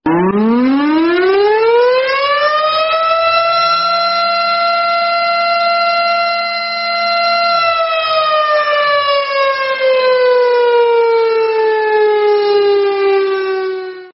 Kategori: Suara bel berbunyi